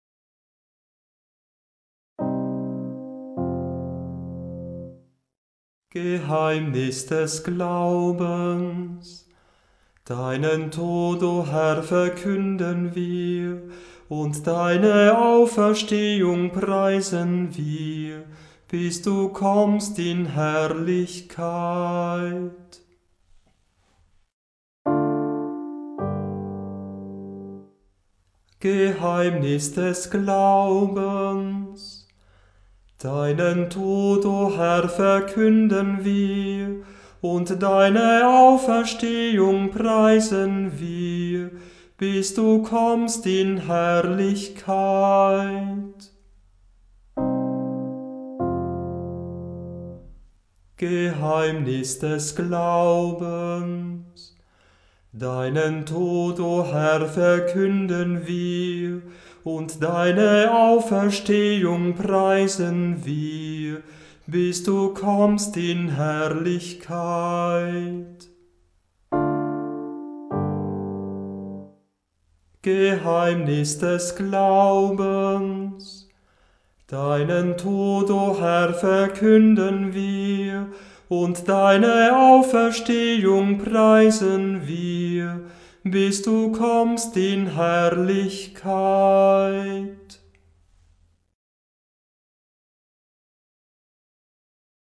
Liturgische Gesänge